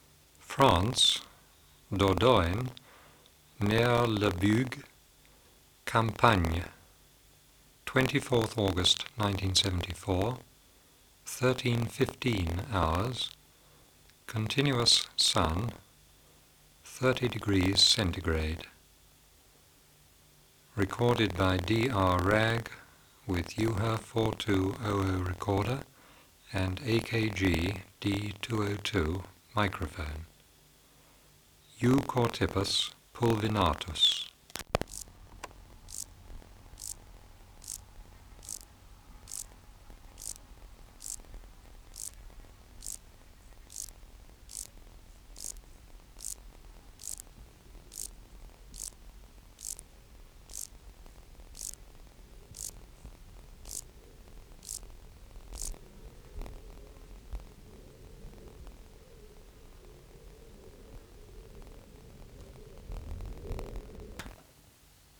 134_3 Euchorthippus pulvinatus | BioAcoustica
Recording Location: Europe: France: Dordogne, near Le Bugue, Campagne
Air Movement: Very light breeze
Substrate/Cage: On grass
Microphone & Power Supply: AKG D202 (-20dB at 50Hz) Distance from Subject (cm): 5